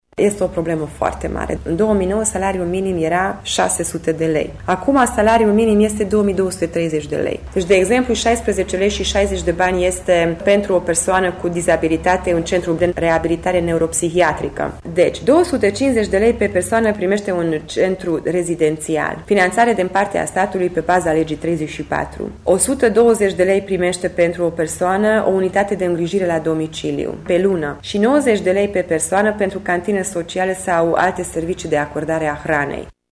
Deputatul Csép Éva Andrea: